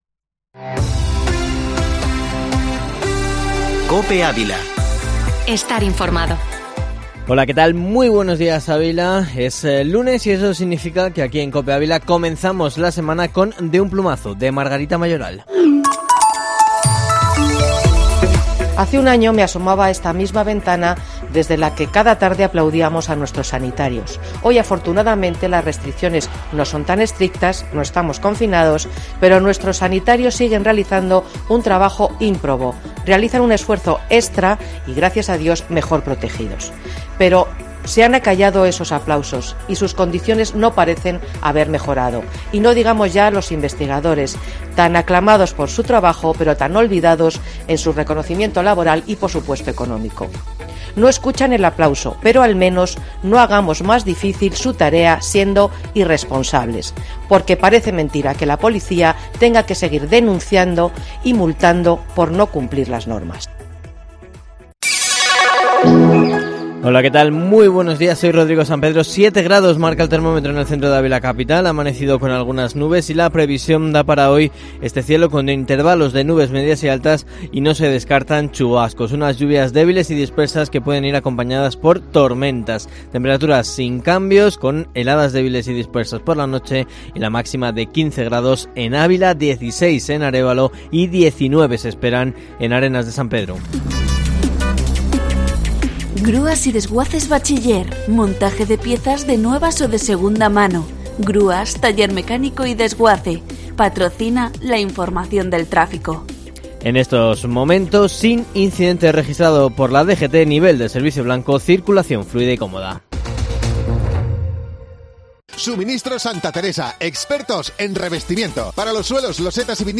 Informativo Matinal de Herrera en COPE Ávila 19/04/2021